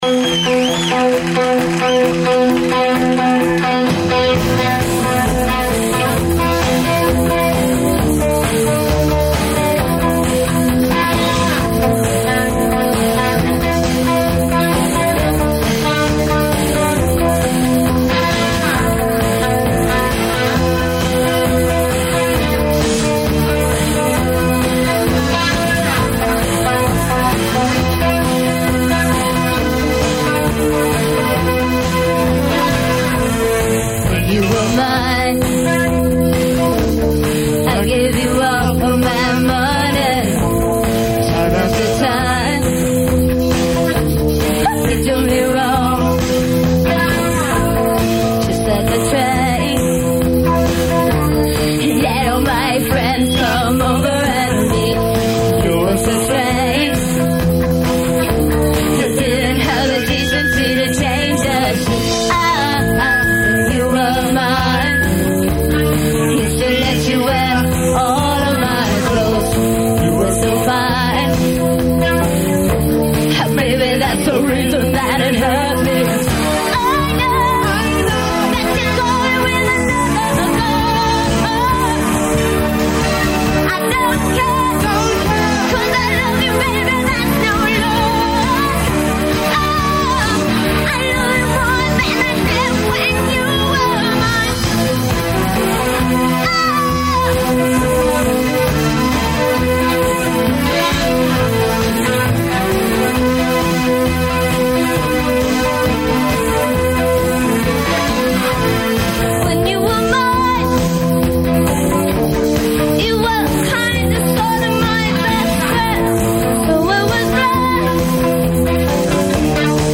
live in 1983